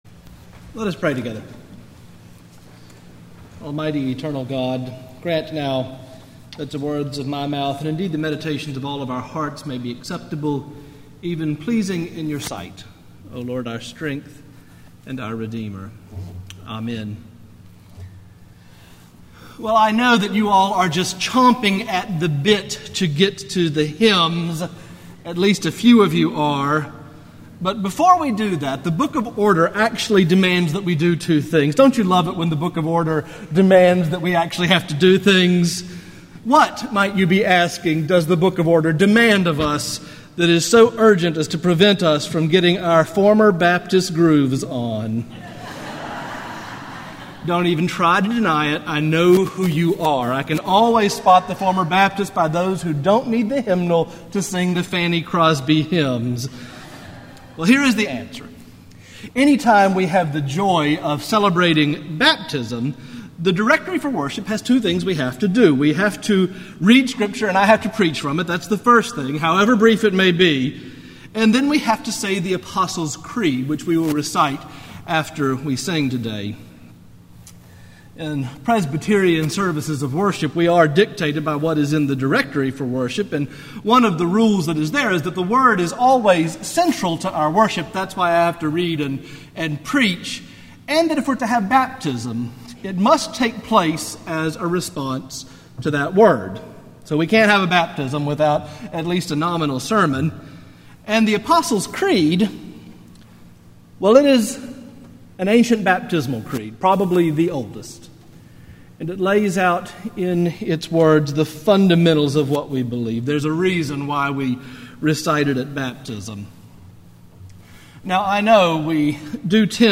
Morningside Presbyterian Church - Atlanta, GA: Sermons: Strengthening Your Inner Being
Morningside Presbyterian Church - Atlanta, GA